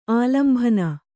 ♪ ālambhana